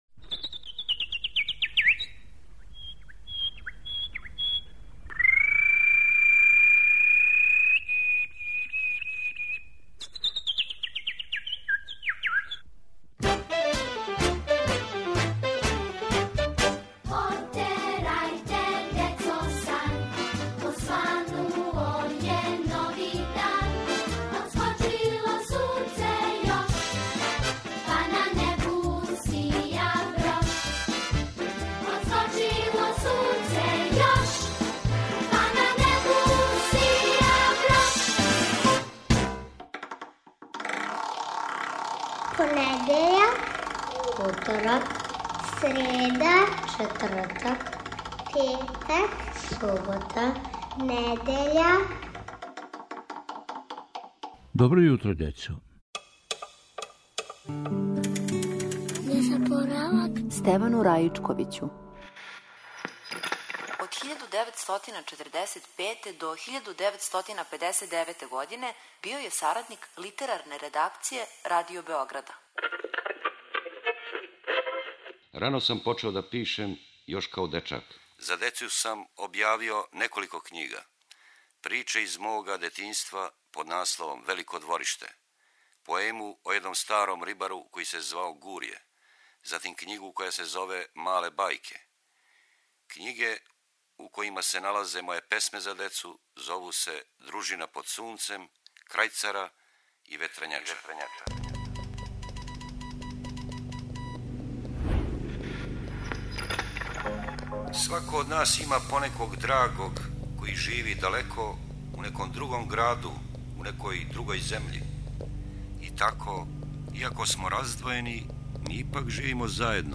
Током маја, недељом, слушате поезију Стевана Раичковића.